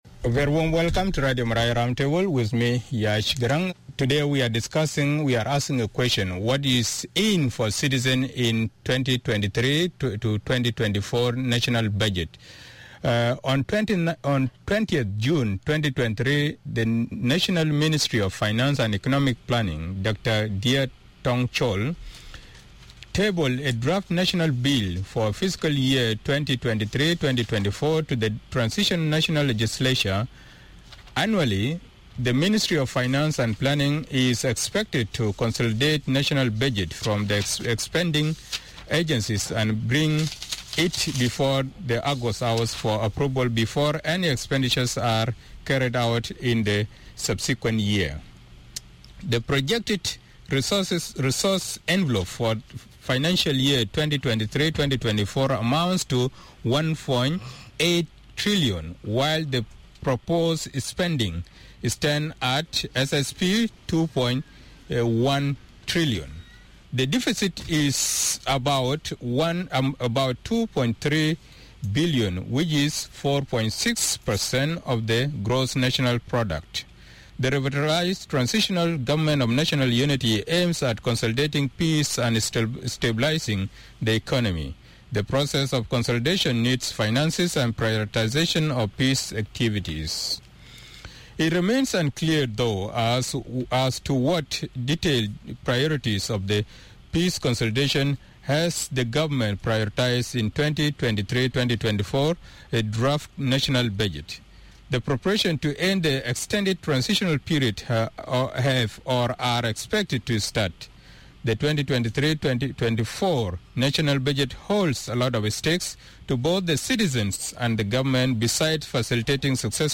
Hon Changkuoth Bichiok: Chairman of Specialized Committee of Finance and economic planning RTNLA 2. Hon. James Magok: Acting Chairman of Specialized Committee of Finance and Economic Planning 3.